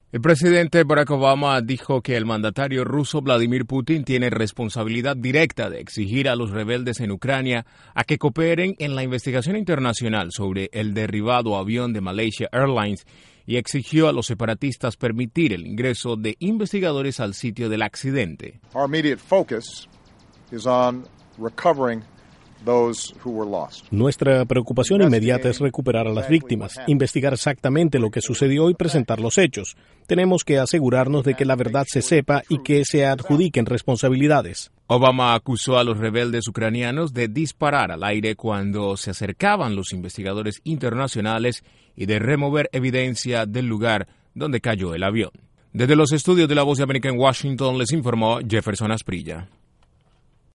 INTRO: En una alocución desde los jardines de la Casa Blanca, el presidente Obama exigió a Rusia ordenar a los rebeldes a que cooperen con la investigación.